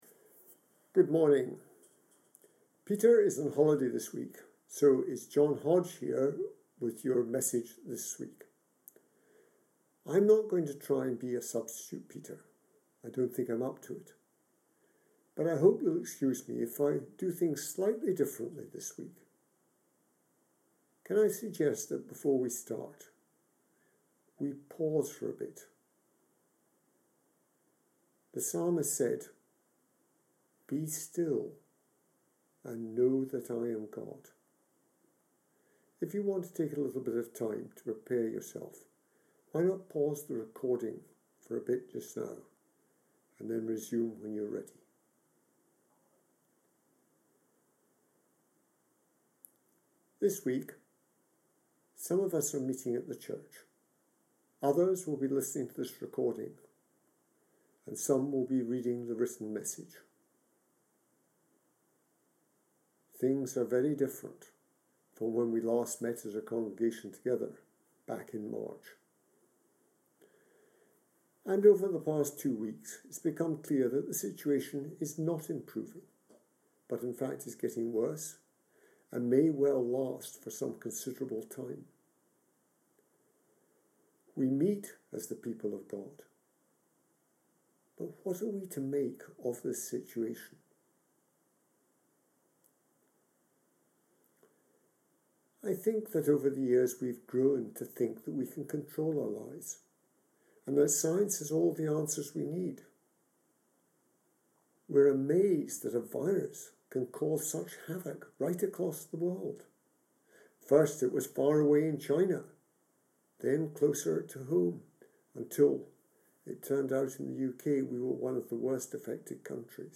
Sermon Sunday 18 October 2020